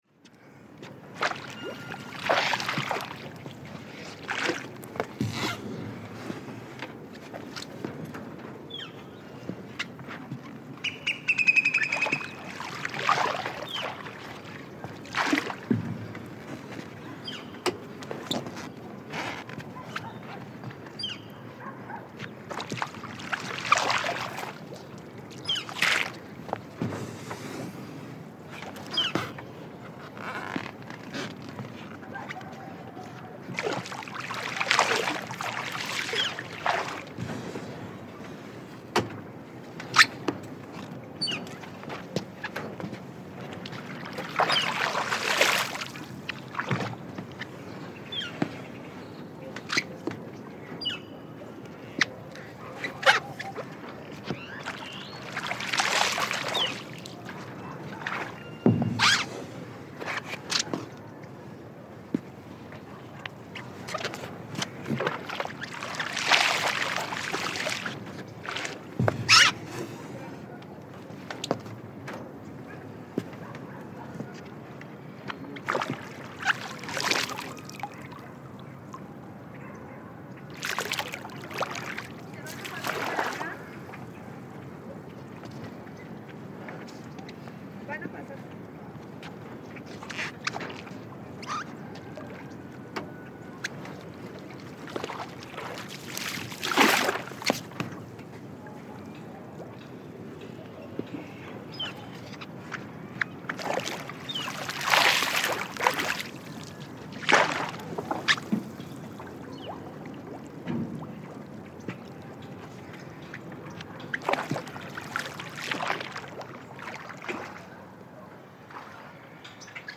Traditional trajinera boat through Xochimilco canals. Stereo 48kHz 24bit.